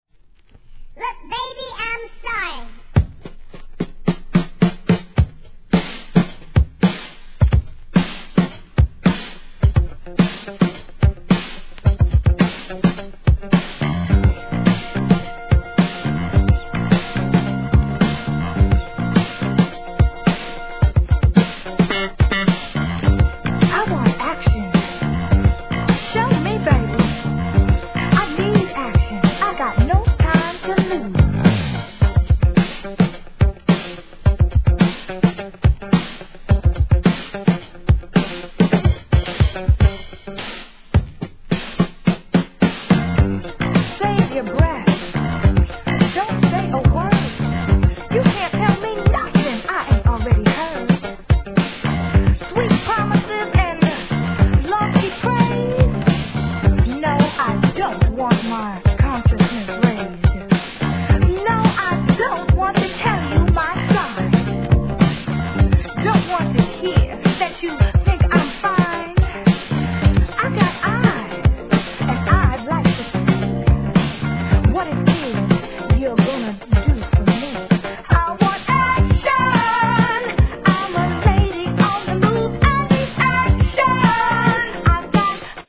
SOUL/FUNK/etc... 店舗 数量 カートに入れる お気に入りに追加 叩きつける強烈なブレイクで定番!